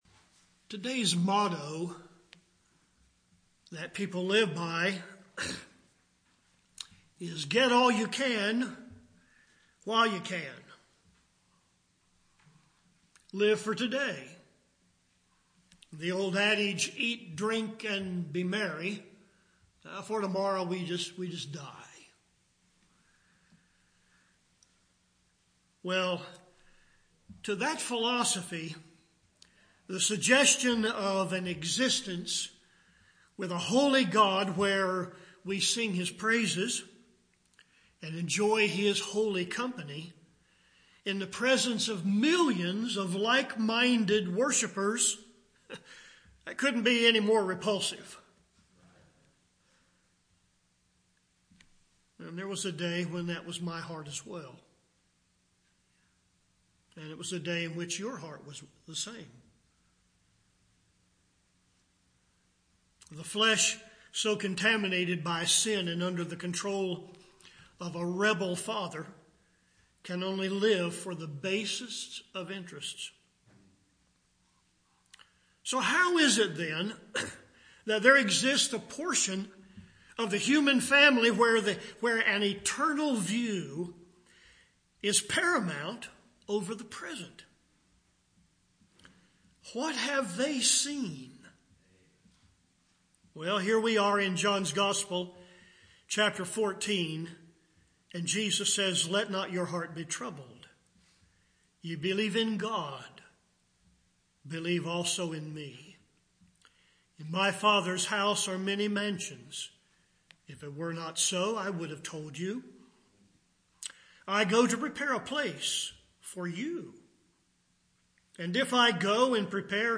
Grace Fellowship Baptist Church, Arden, NC